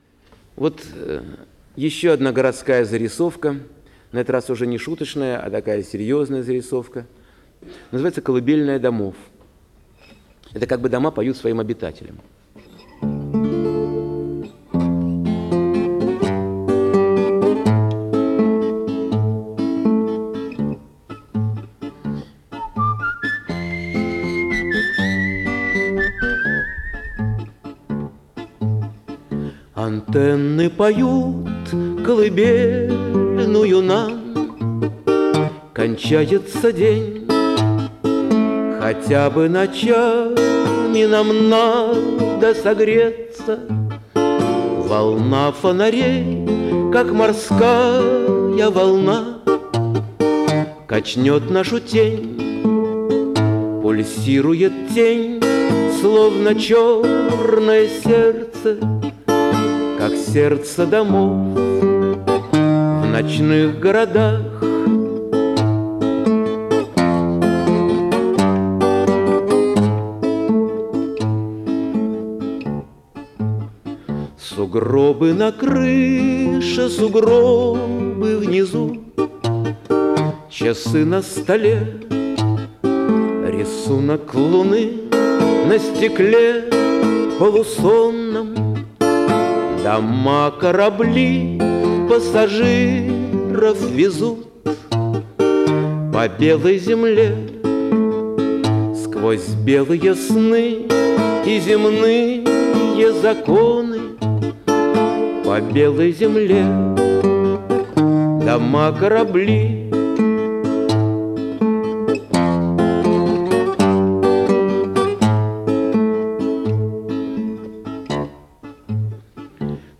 Авторское исполнение: